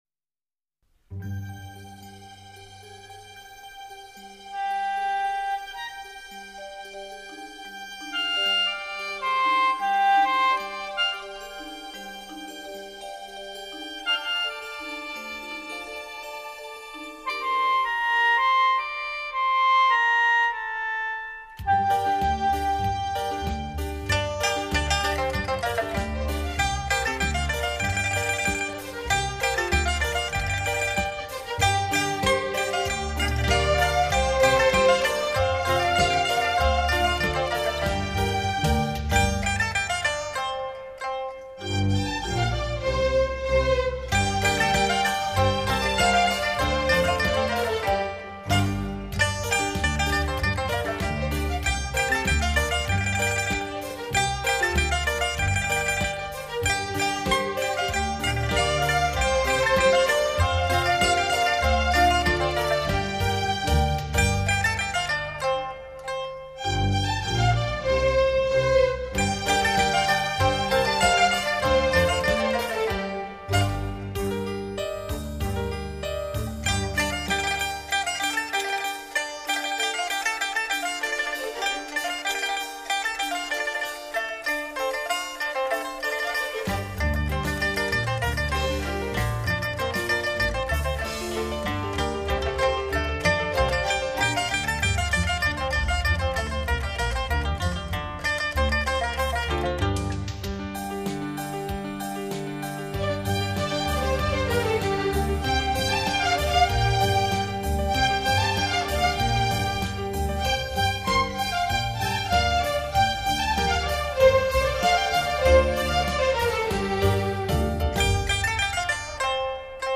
青海民歌
编曲配器细腻
最新数码录音